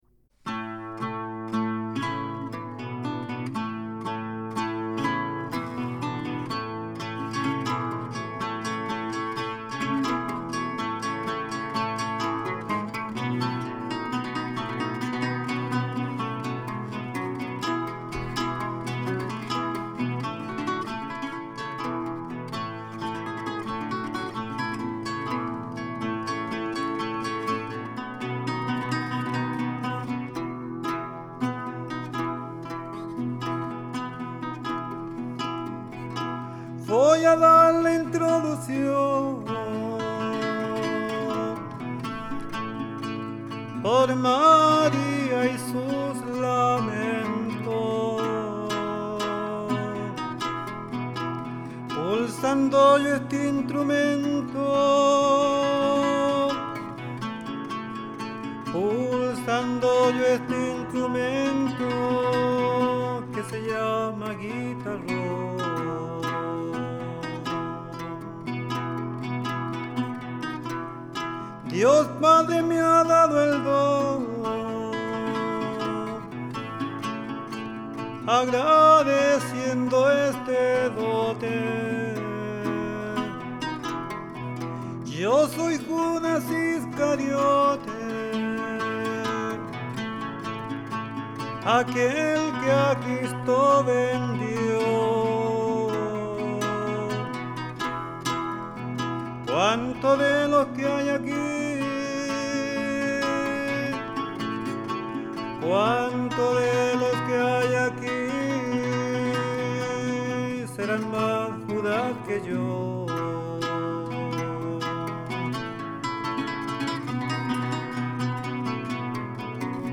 El guitarronero